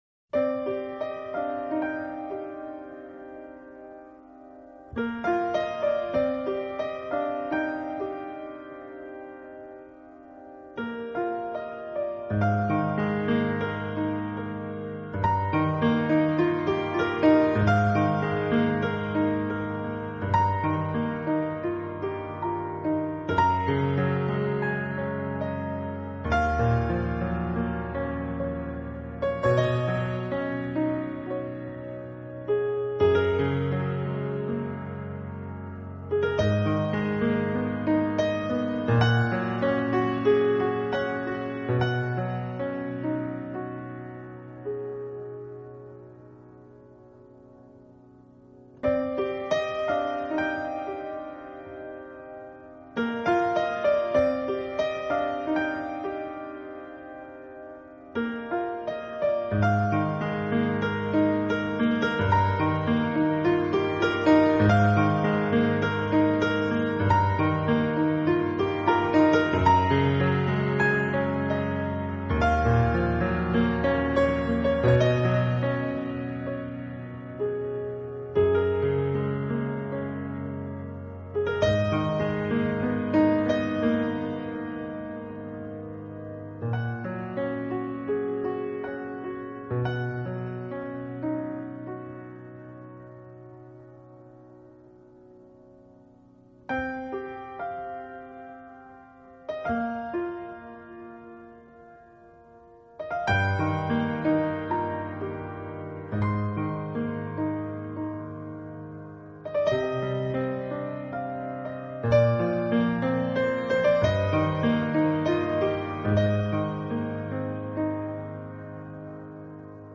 音乐风格：Piano solo, New Age
keyboards and guitar as well as piano